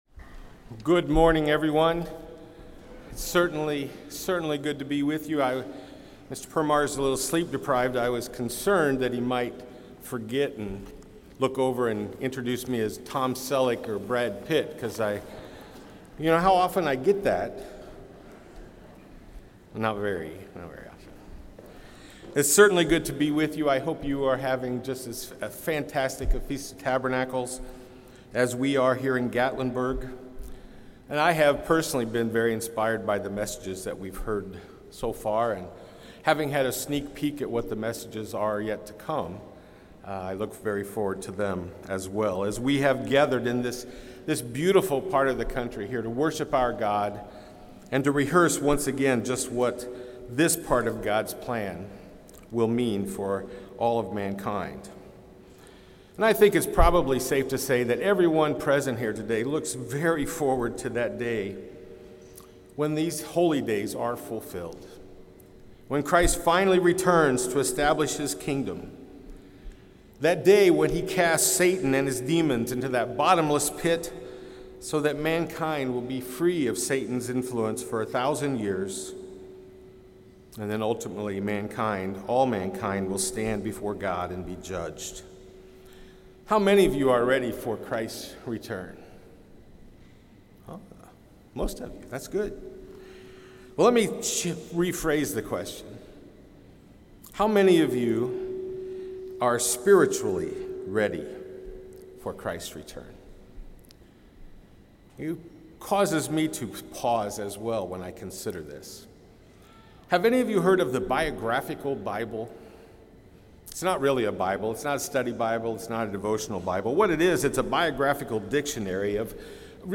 This sermon was given at the Gatlinburg, Tennessee 2024 Feast site.